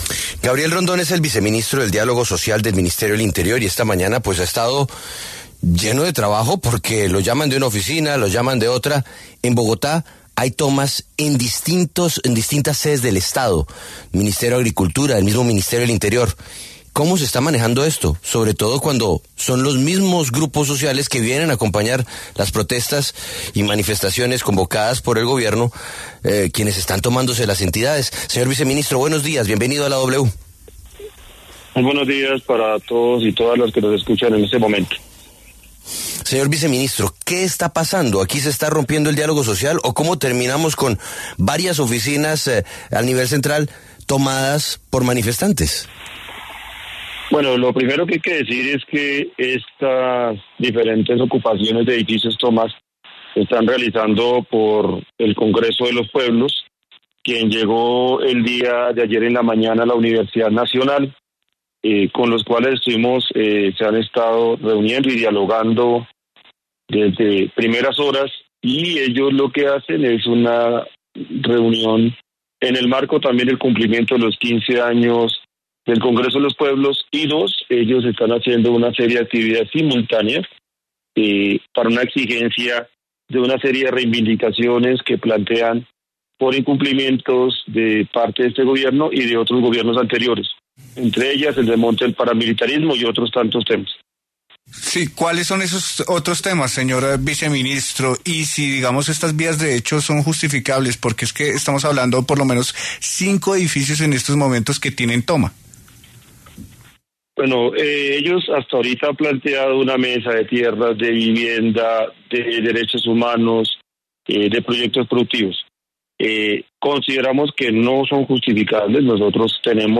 Gabriel Rondón, viceministro de Diálogo Social, pasó por los micrófonos de La W para hablar sobre el tema, tomas en distintas sedes del estado, ministerios, grupos sociales que acompañan las protestas.